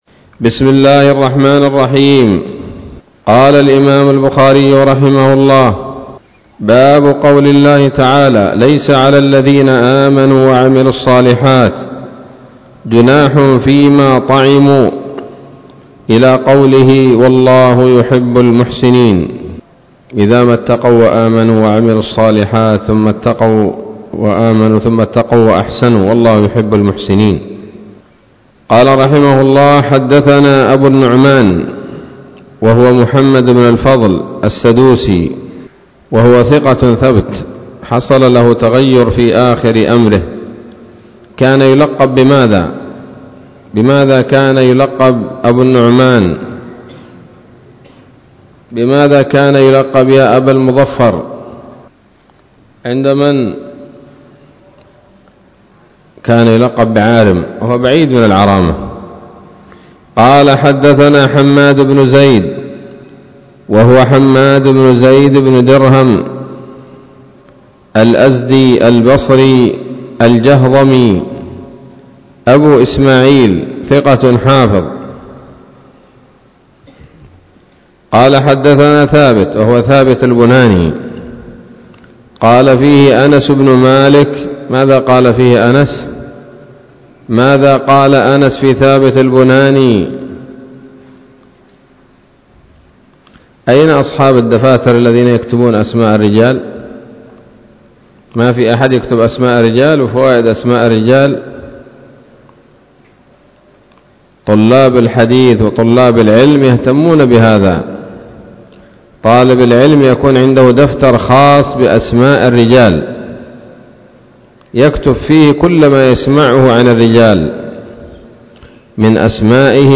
الدرس الرابع والتسعون من كتاب التفسير من صحيح الإمام البخاري